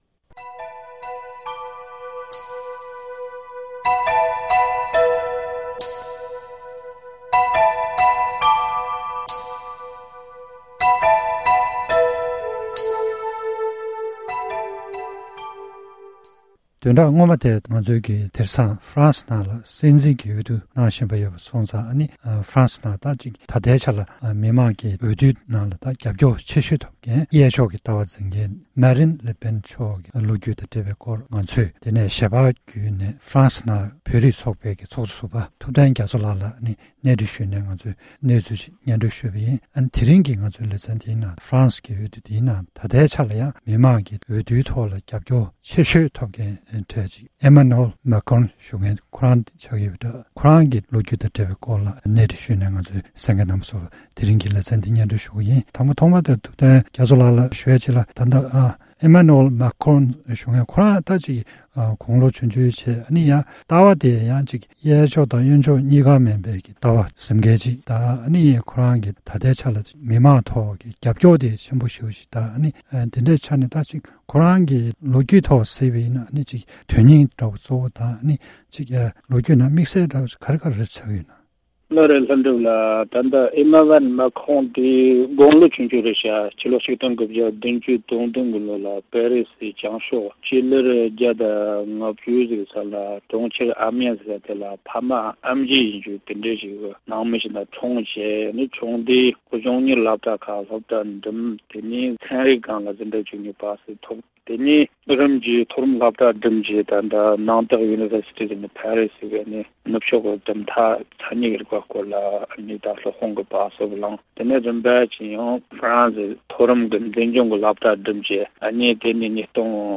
སྒྲ་ལྡན་གསར་འགྱུར།
གནས་འདྲི་ཞུས་པ་ཞིག